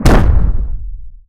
rock_impact_heavy_slam_03.wav